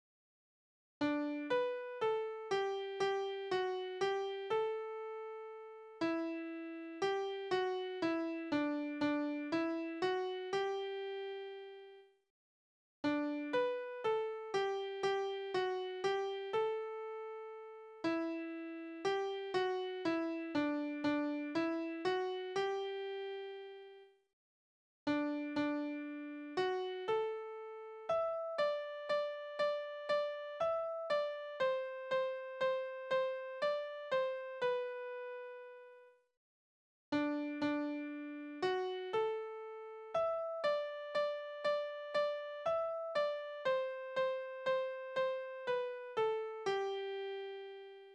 Tonart: G-Dur
Taktart: 3/4
Tonumfang: große None
Besetzung: vokal